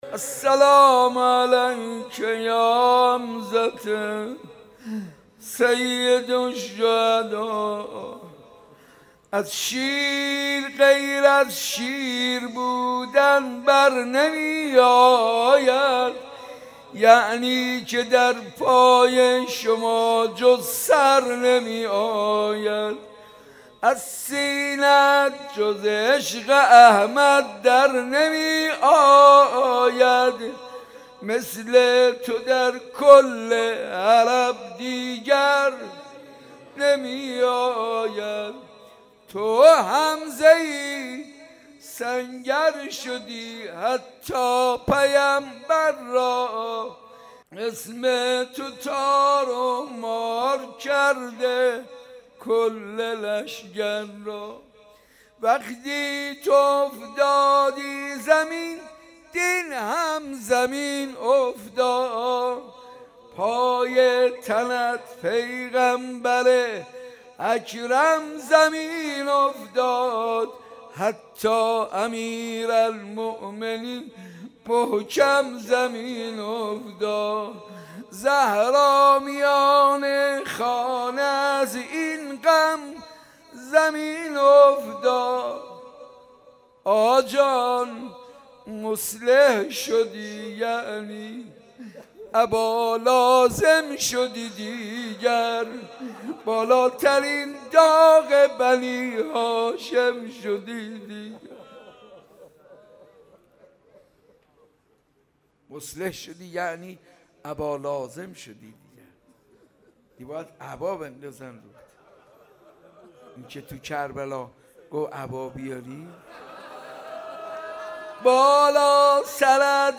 حسینیه ی صنف لباس فروش ها
از شیر غیر از شیر بودن برنمی آید | روضه ی حضرت حمزه سیدالشهدا علیه السلام